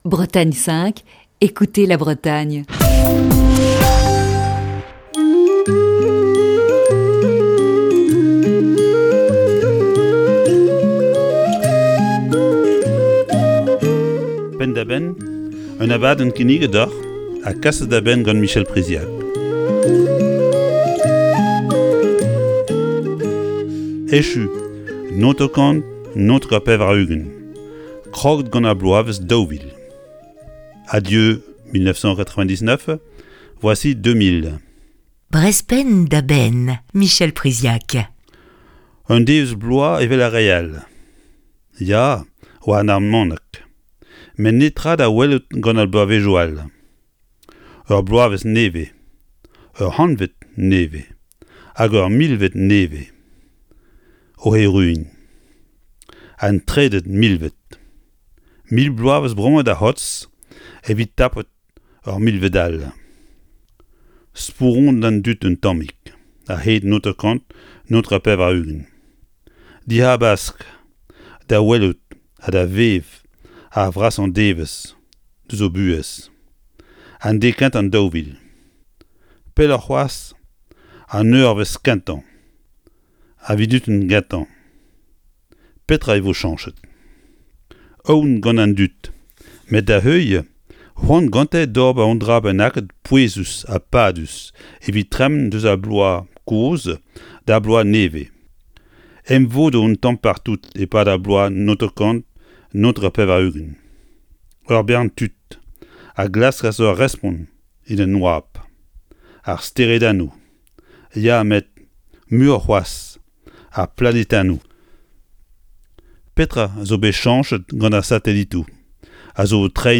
Chronique du 17 août 2020.